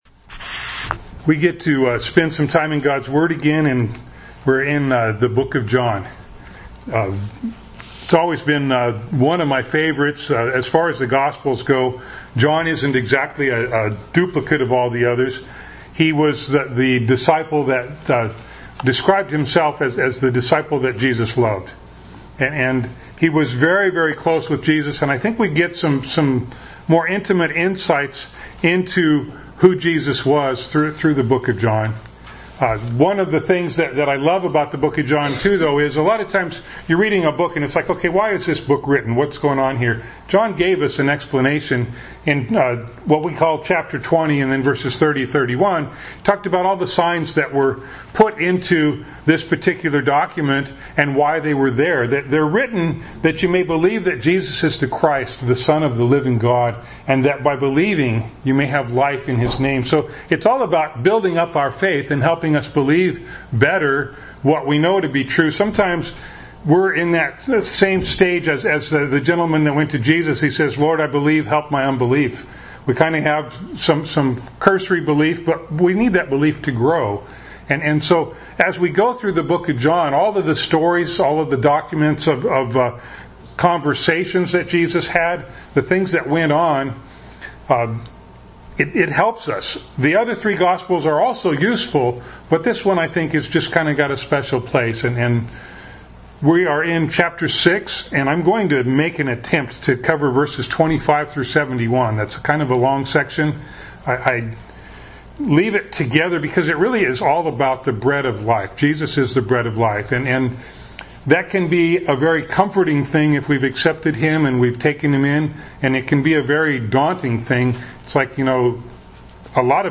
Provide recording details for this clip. John 6:25-71 Service Type: Sunday Morning Bible Text